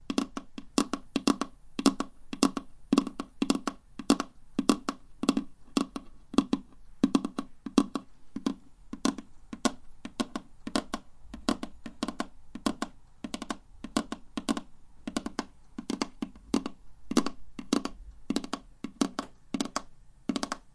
手指放在桌子上
描述：在等待期间，在桌上的手指。
Tag: 点击 按扣 手指敲击 手指 fingersnap 噪音